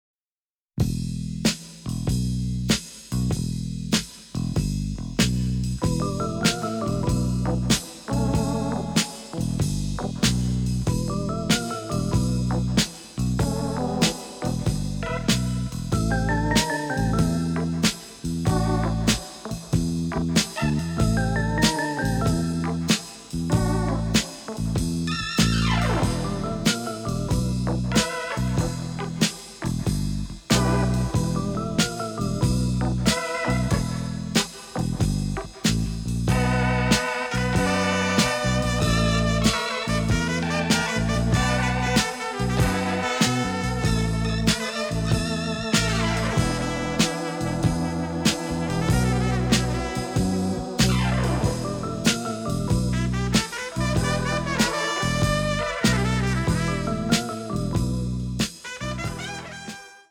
in stereo and mint condition